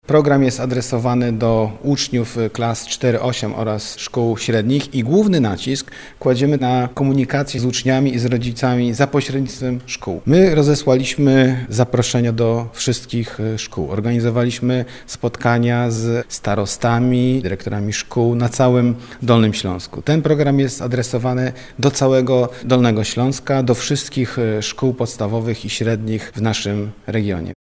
Mówi Marcin KrzyżanowskiWicemarszałek Województwa Dolnośląskiego.